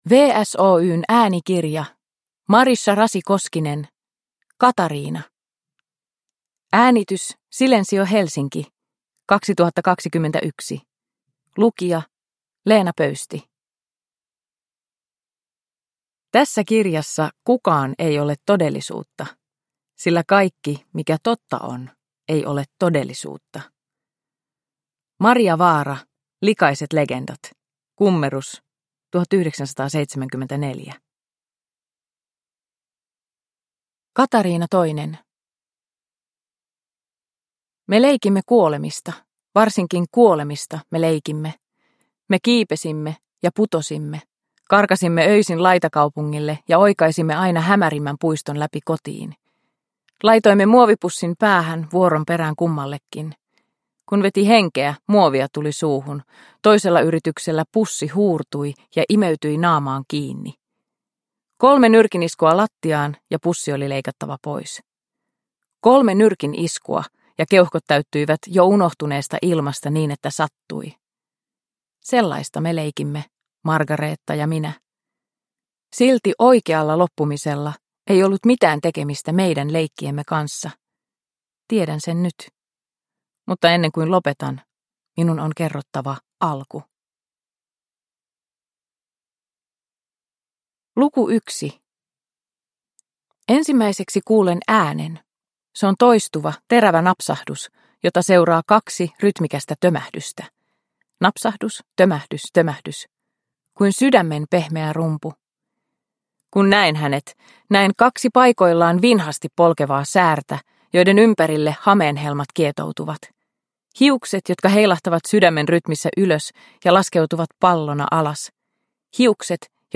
Katariina – Ljudbok – Laddas ner